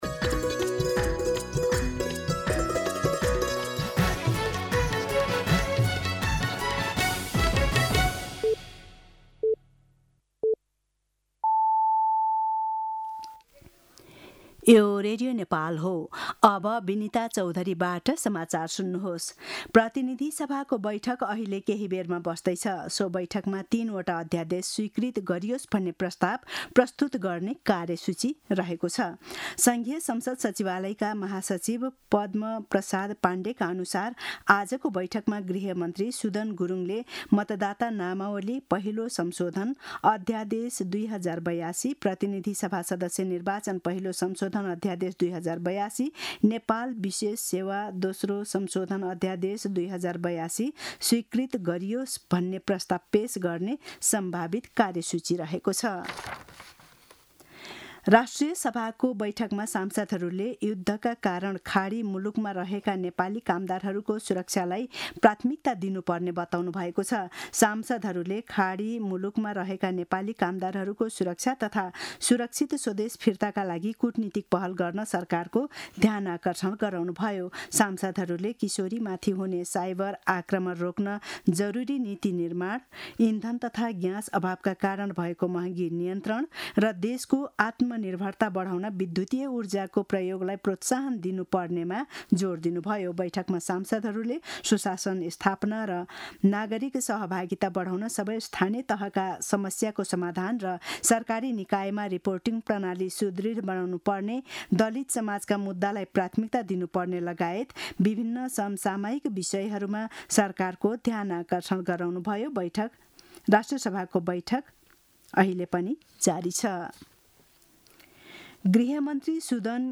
दिउँसो १ बजेको नेपाली समाचार : २३ चैत , २०८२
1-pm-News-23.mp3